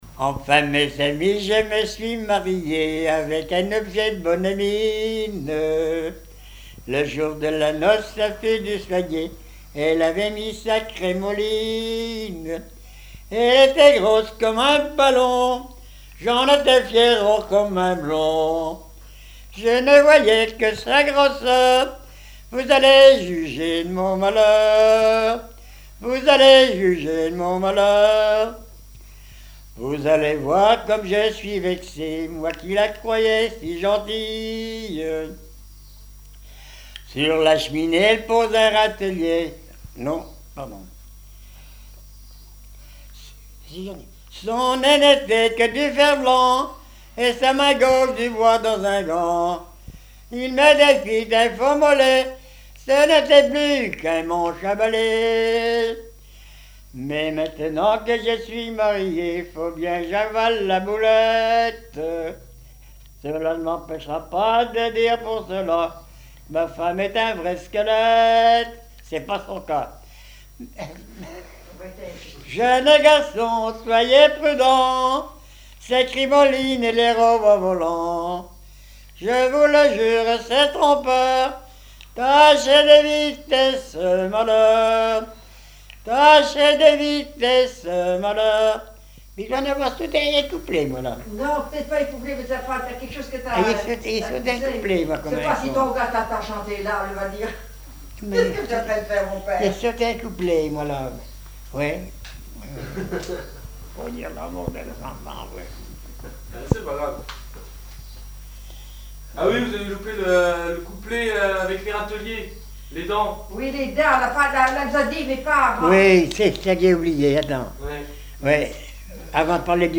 Mémoires et Patrimoines vivants - RaddO est une base de données d'archives iconographiques et sonores.
recueil de chansons populaires
Pièce musicale inédite